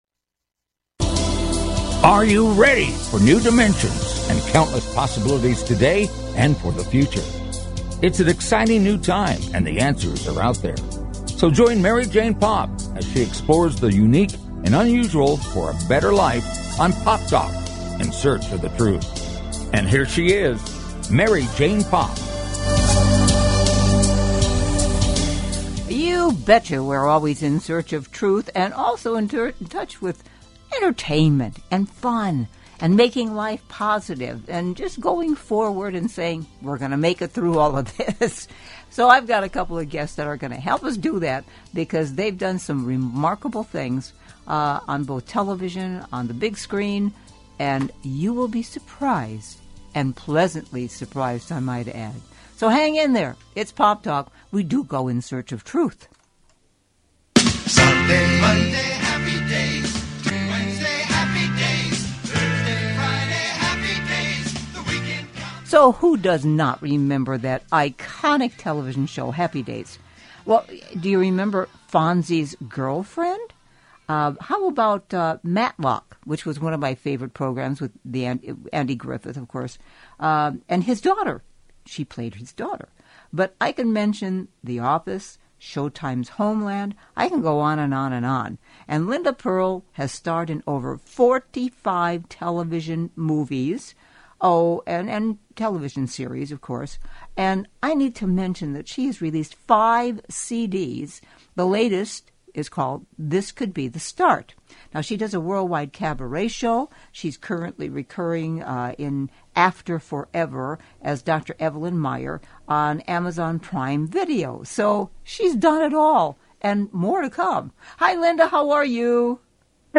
Talk Show Episode
The show is high energy, upbeat and entertaining.